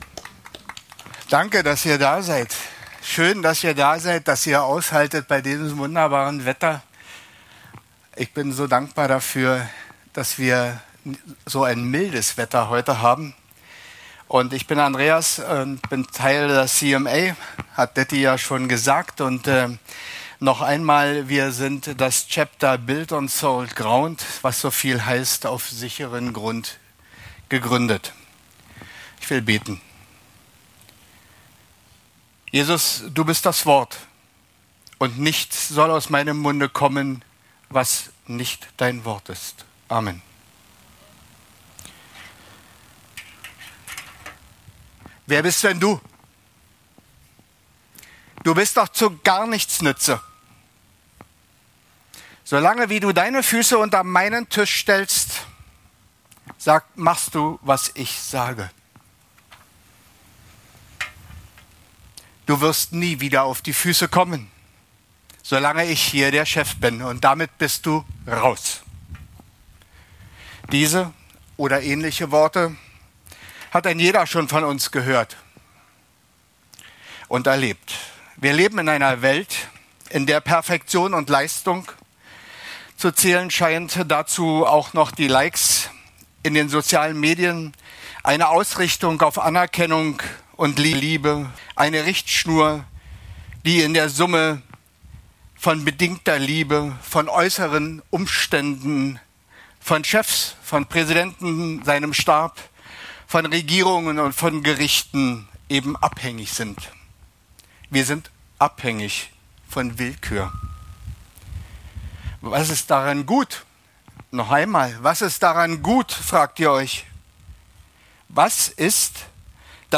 Motorradgottesdienst